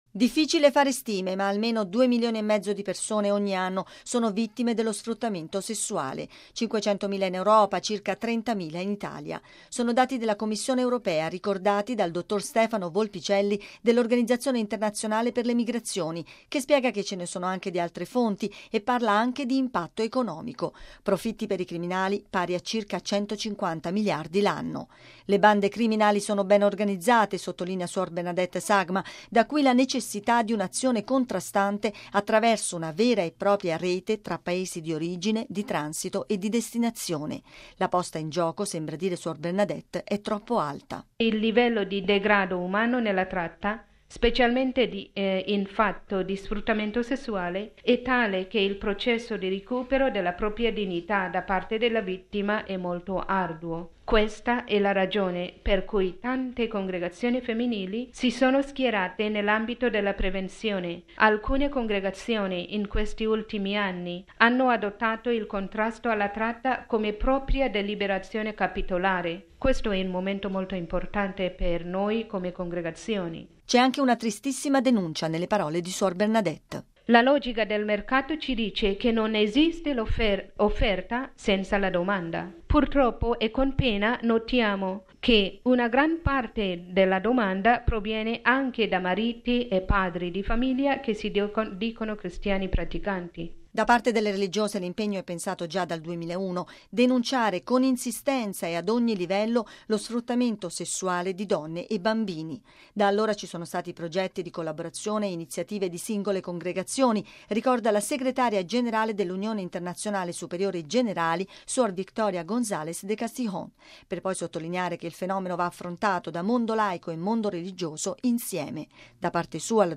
Religiose in rete contro la tratta e lo sfruttamento sessuale di donne e bambini: presentato questa mattina in Sala Stampa della Santa Sede il congresso 2009 organizzato dall’Unione Internazionale Superiore Generali (Uisg) e dall’Organizzazione Internazionale per le Migrazioni (Oim) proprio sul tema della moderna schiavitù dell’abuso sessuale.